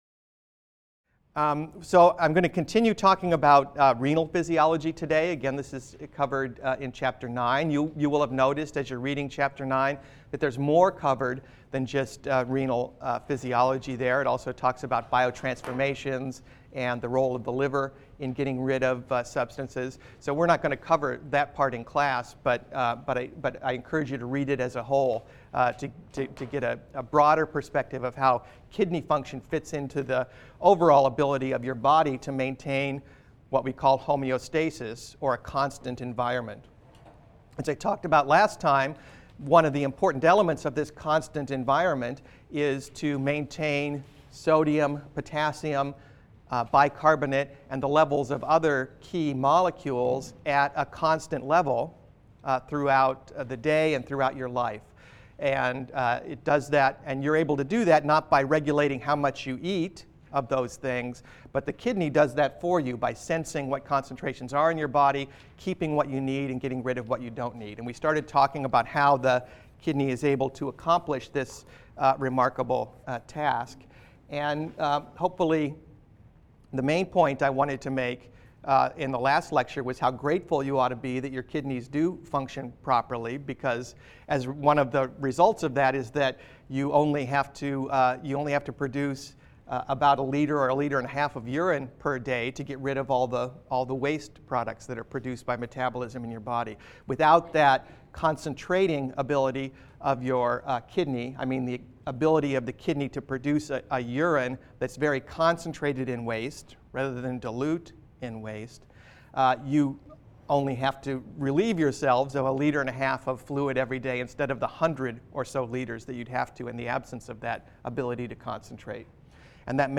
BENG 100 - Lecture 17 - Renal Physiology (cont.)